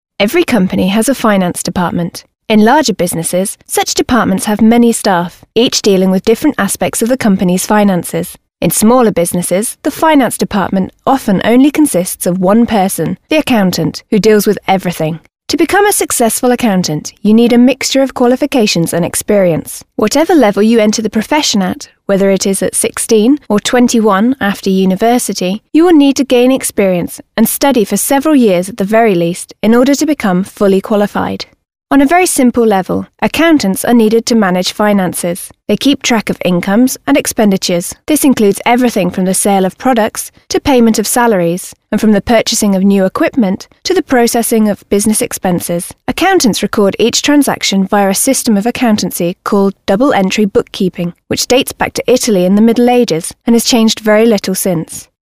2. dialog lub monolog prezentujący nowe słówka i potrzebne zwroty